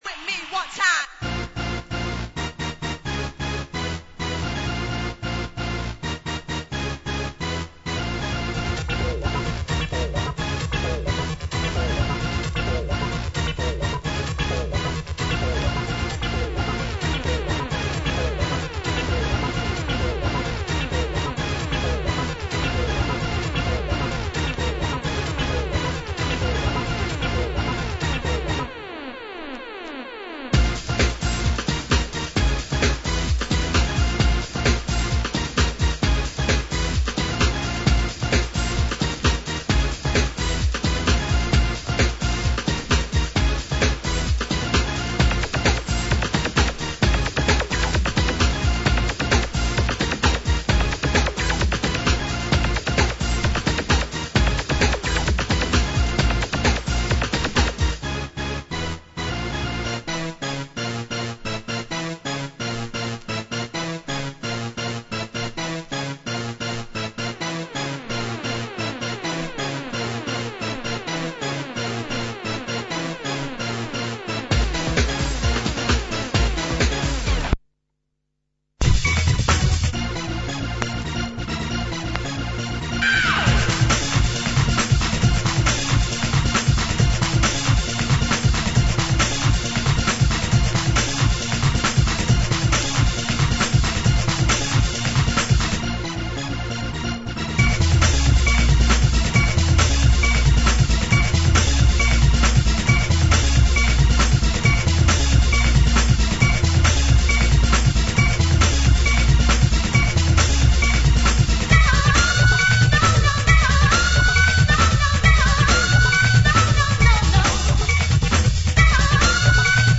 Old-Skool
Hardcore, Breakbeat